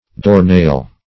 Doornail \Door"nail`\ (d[=o]r"n[=a]l`), n.